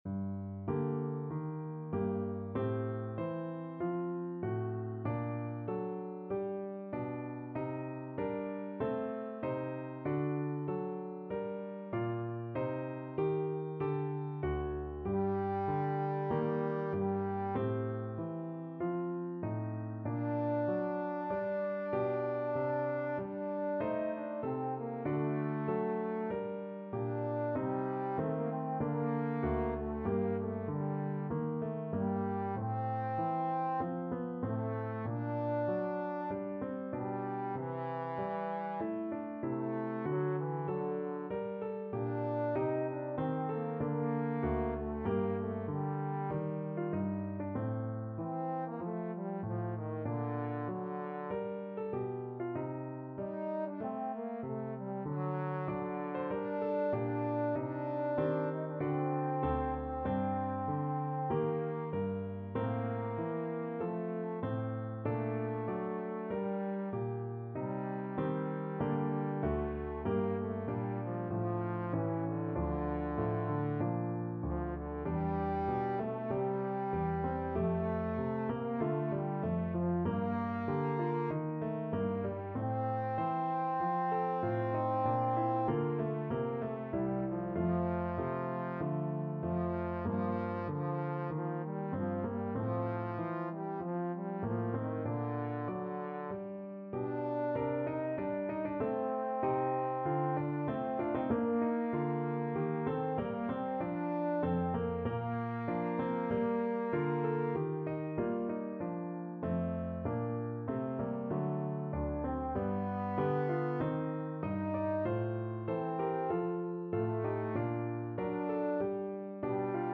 Classical Purcell, Henry Music for a While, from Oedipus Z.583 Trombone version
Play (or use space bar on your keyboard) Pause Music Playalong - Piano Accompaniment Playalong Band Accompaniment not yet available transpose reset tempo print settings full screen
Trombone
G minor (Sounding Pitch) (View more G minor Music for Trombone )
4/4 (View more 4/4 Music)
Classical (View more Classical Trombone Music)